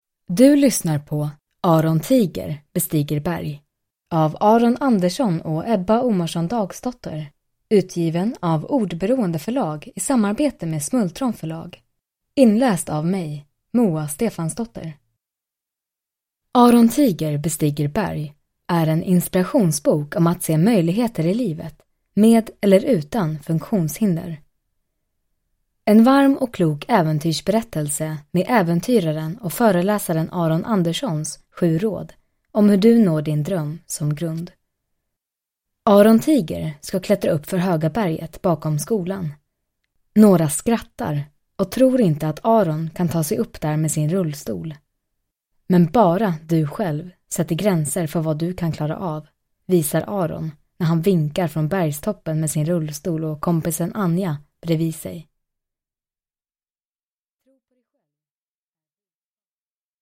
Aron Tiger bestiger berg – Ljudbok – Laddas ner